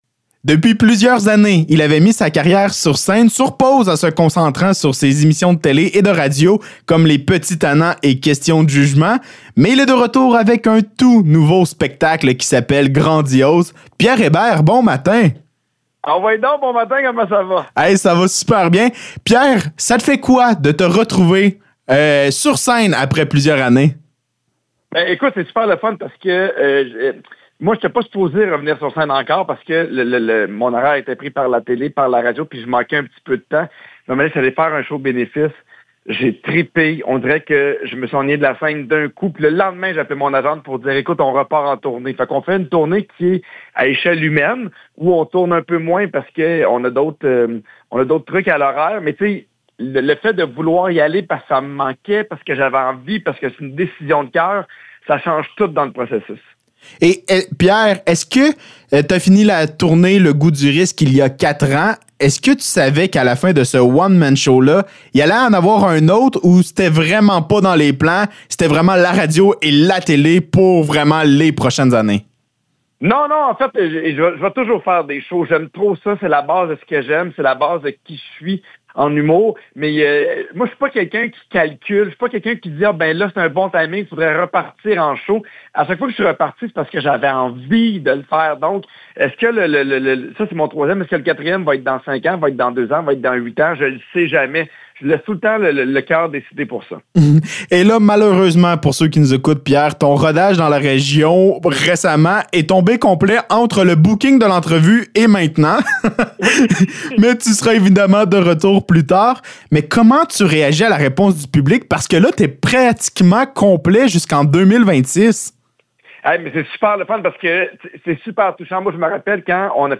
Entrevue avec Pierre Hébert